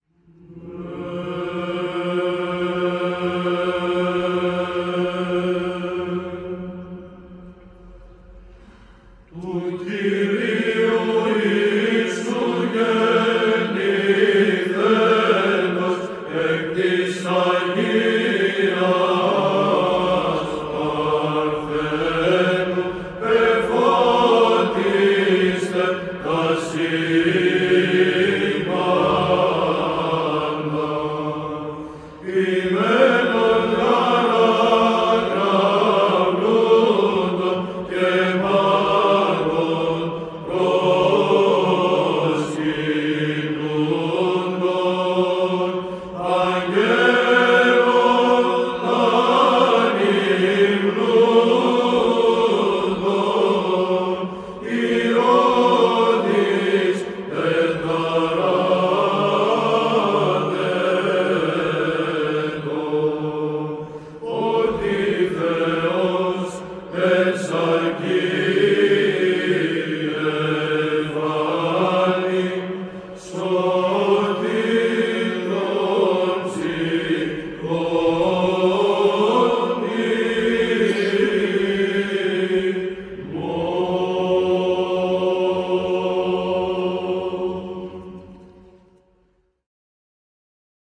ΒΥΖΑΝΤΙΝΗ ΧΟΡΩΔΙΑ ΕΡΕΥΝΗΤΙΚΟΥ ΩΔΕΙΟΥ ΧΑΛΚΙΔΟΣ
Ψάλλει η Β.Χ.Ε.Ω. Χαλκίδος
ΤΙΤΛΟΣ:  Του Κυρίου Ιησού... Στιχηρό Ιδιόμελο Εσπερινού.
ΗΧΟΣ:   Δεύτερος.